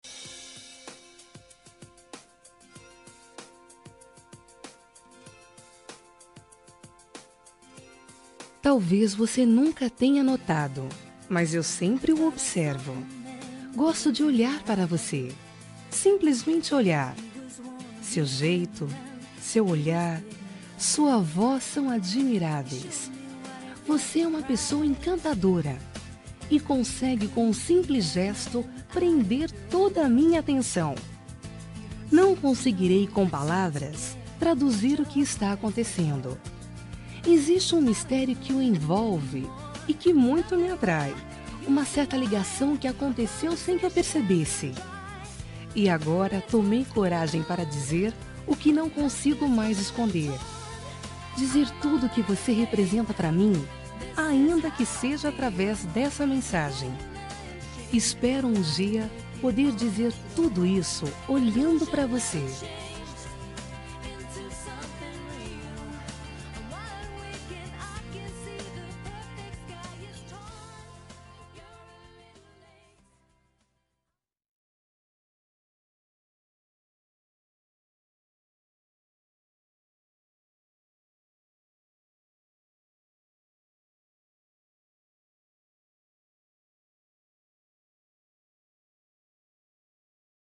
Telemensagem Paquera – Voz Feminina – Cód: 051586